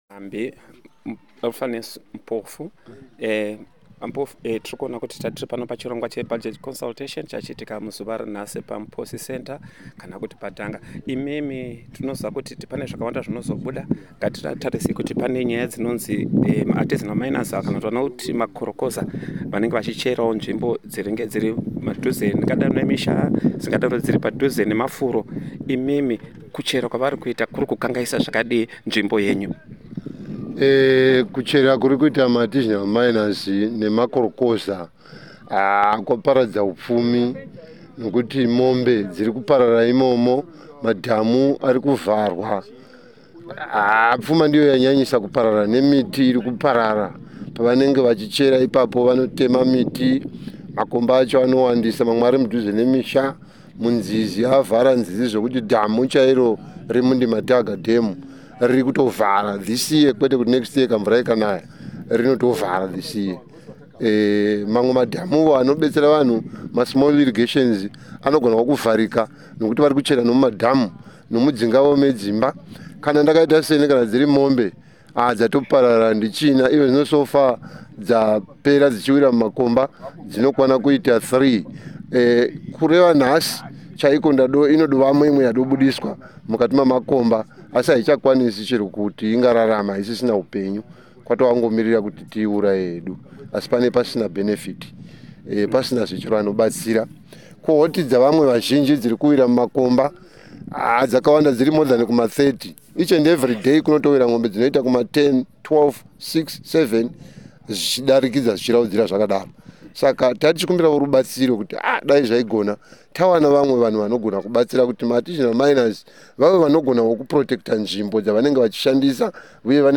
Listen to a village and community leader lamenting the loss of village cattle to mining pits in the district of Mberengwa. The pits are left behind largely by artisanal gold and lithium miners. After extracting the resources, the miners do not bother to cover the pits and reclaim the land.